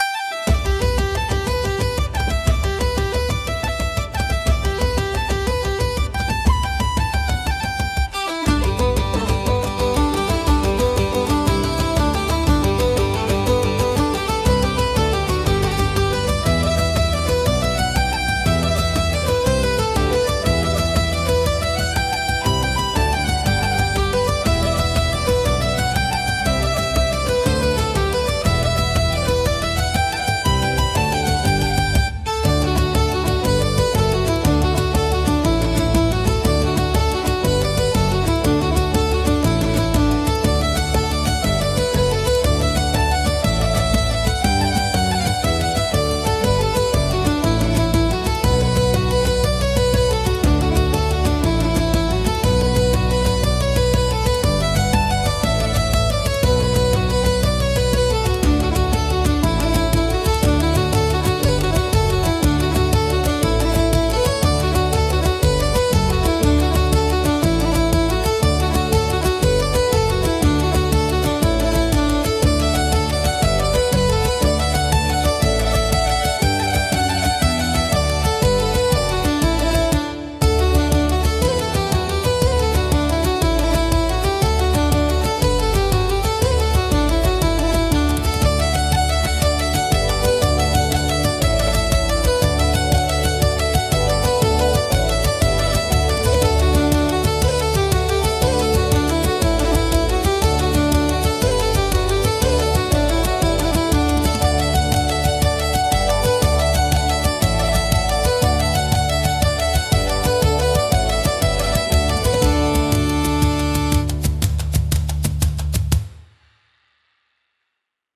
神秘的で豊かな民族色があり、物語性や異国情緒を演出するシーンに効果的なジャンルです。